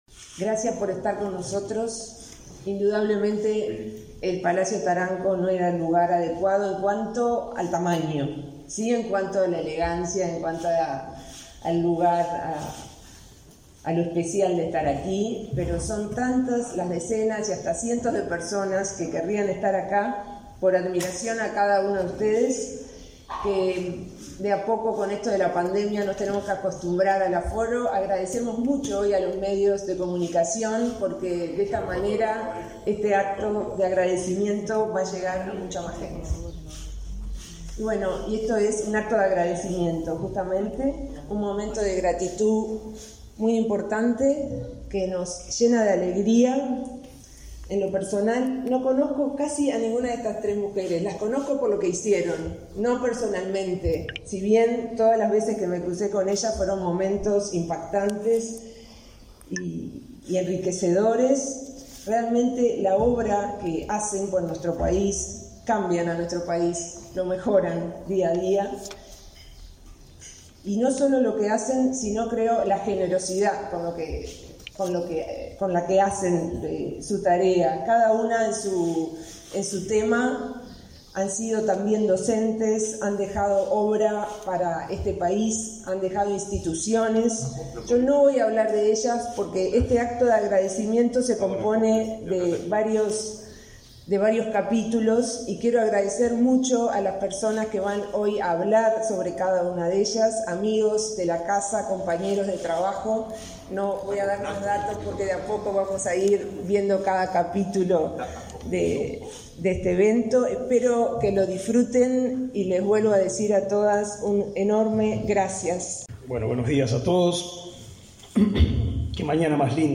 Palabras de autoridades del Ministerio de Educación y Cultura
Palabras de autoridades del Ministerio de Educación y Cultura 16/12/2021 Compartir Facebook X Copiar enlace WhatsApp LinkedIn La directora de Cultura, Mariana Wanstein, y el ministro Pablo da Silveira participaron, este jueves 16 en el Palacio Taranco, de la entrega de la medalla Delmira Agustini a personalidades de la cultura.